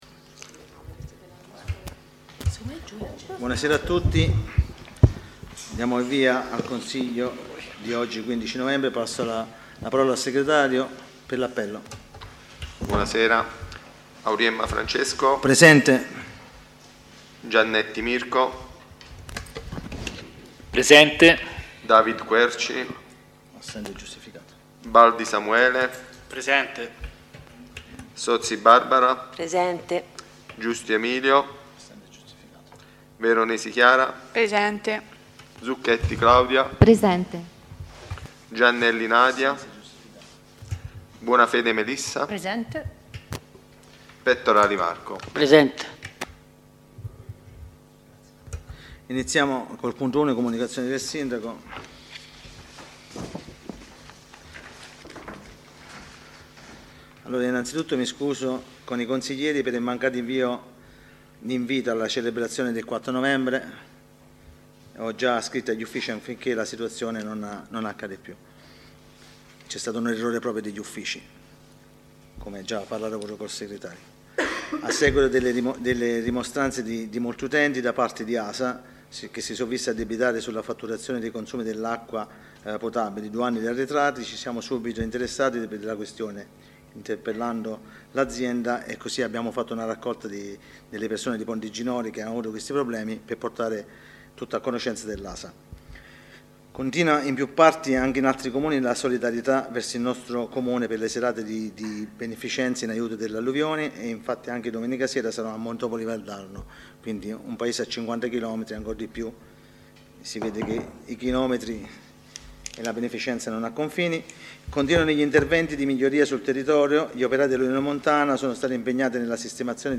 Consiglio Comunale del 15/11/2024 Comune di Montecatini Val di Cecina